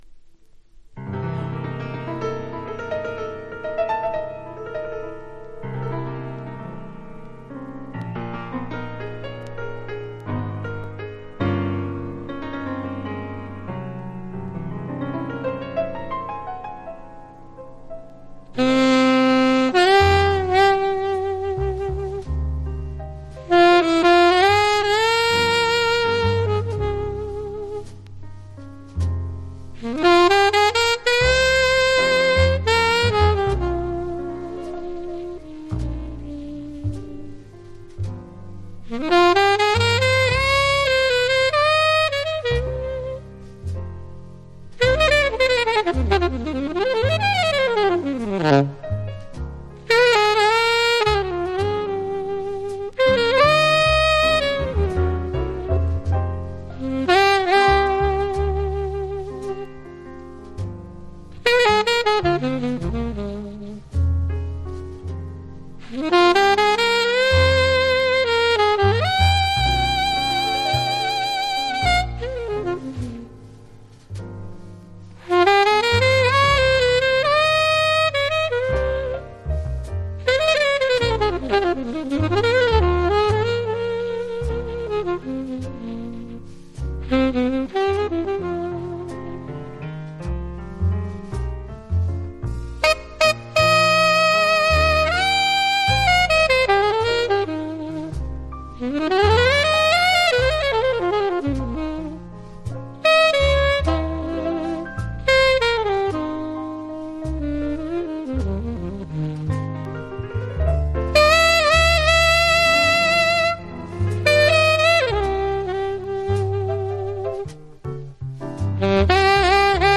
Genre US JAZZ